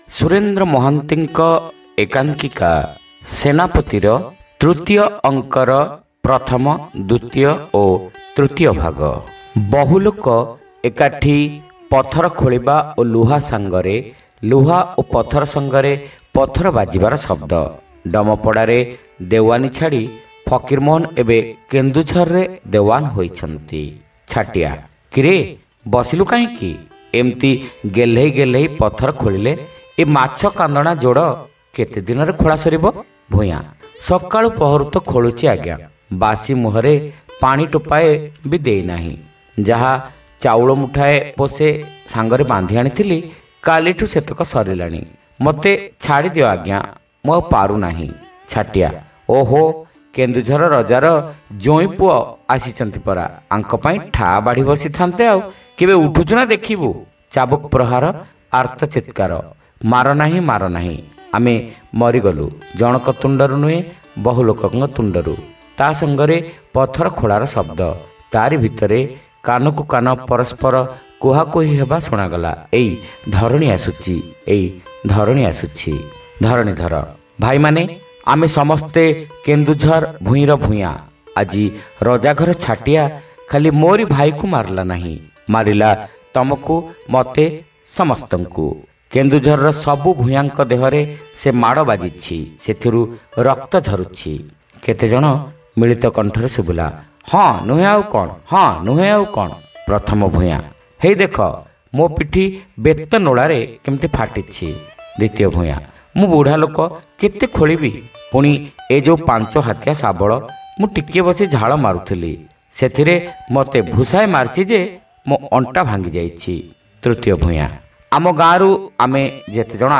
ଶ୍ରାବ୍ୟ ଏକାଙ୍କିକା : ସେନାପତି (ପଞ୍ଚମ ଭାଗ)